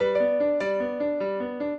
piano
minuet12-5.wav